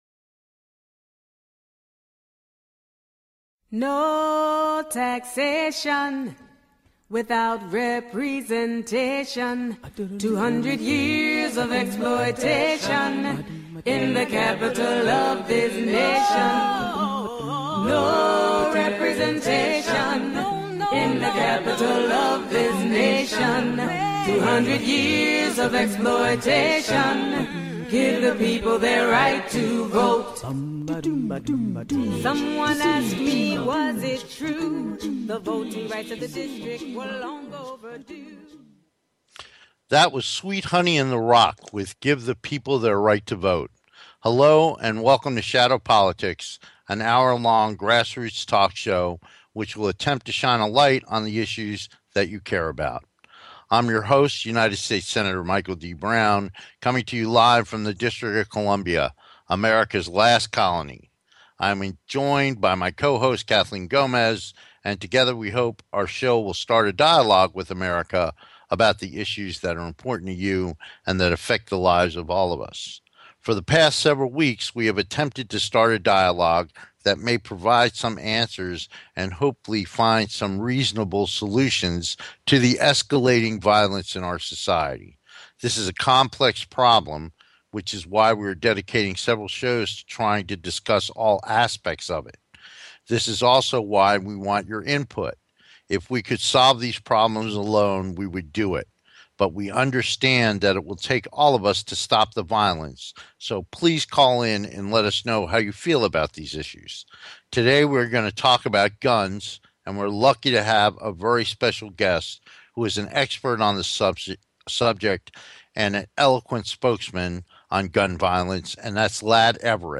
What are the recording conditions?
We look forward to having you be part of the discussion so call in and join the conversation.